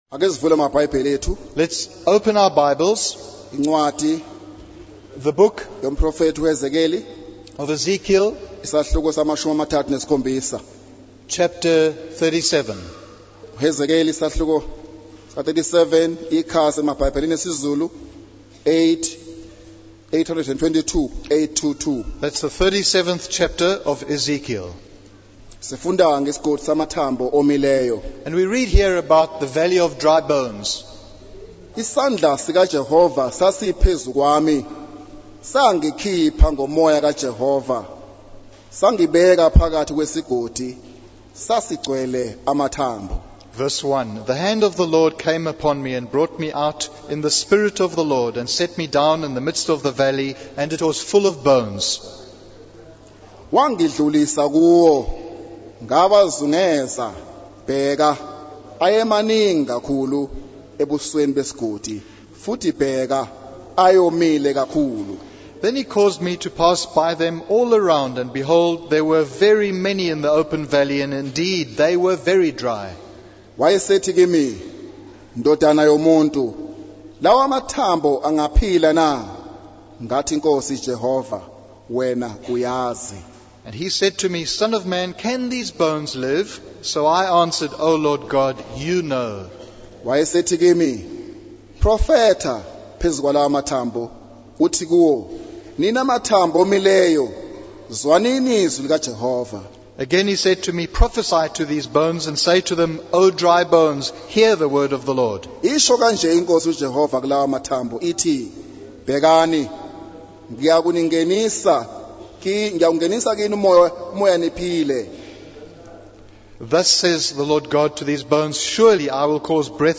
In this sermon, the preacher begins by inviting God to be present and bless his word. The main message of the sermon is that God is able to revive and restore what seems impossible or useless. The preacher uses the story of Lazarus being raised from the dead as an example of God's power to bring life to hopeless situations.